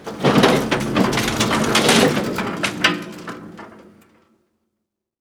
gurneyload.wav